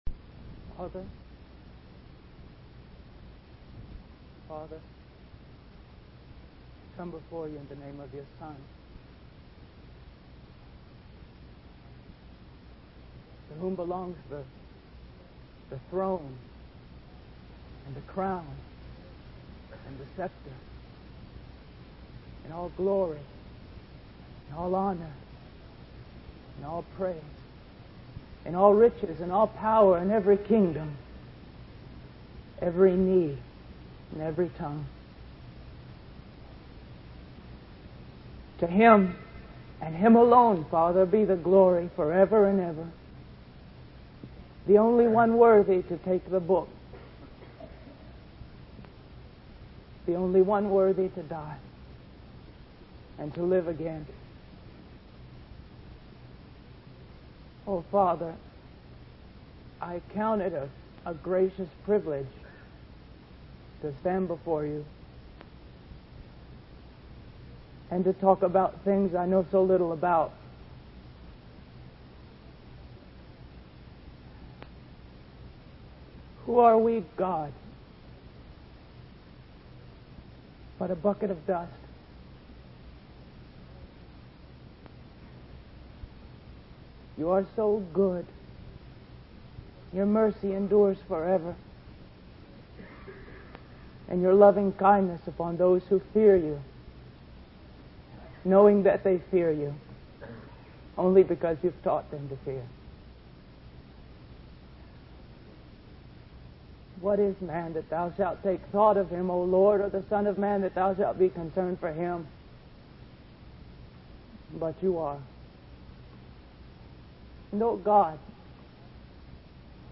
In this sermon, the preacher emphasizes the importance of the Gospel of Jesus Christ.